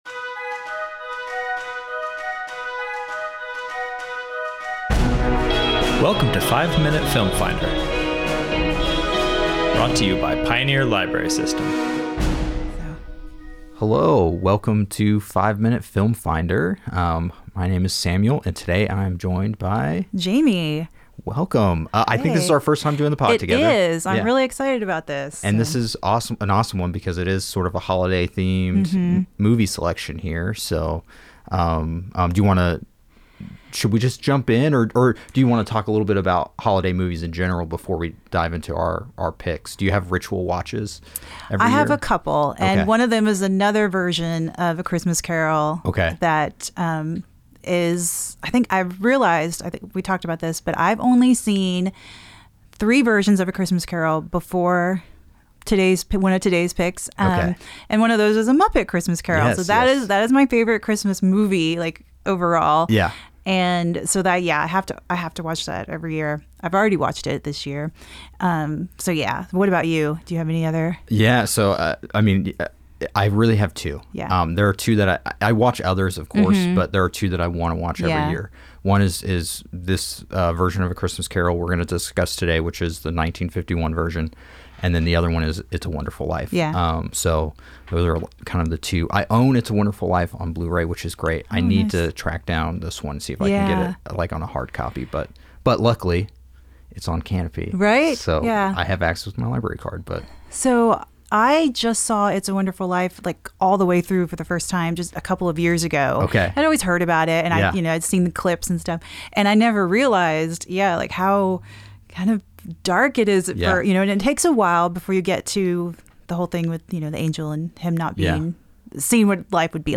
Our hosts have five minutes to inform and sell you on the movies covered in this episode.